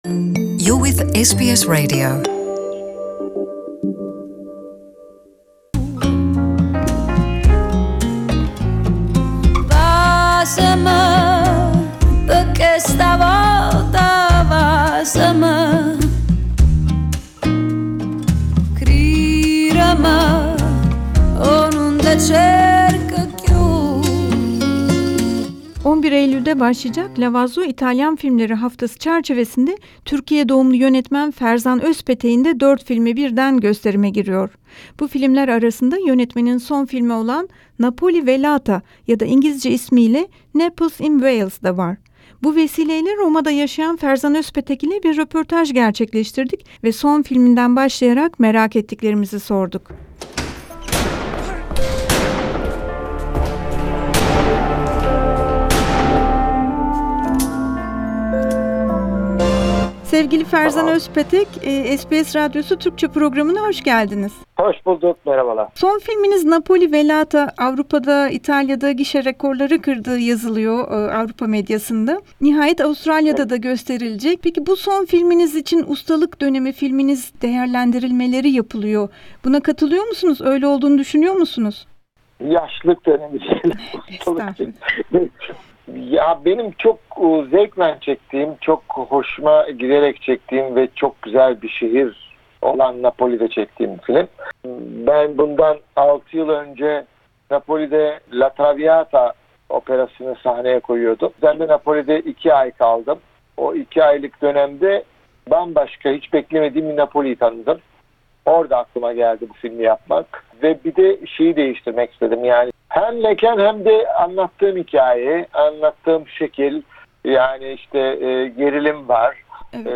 Özpetek'le, gizemli Napoli şehrinin başrolde olduğu son filmini ve Avrupa'da değişen politik iklimin kendisini nasıl etkilediğini konuştuk.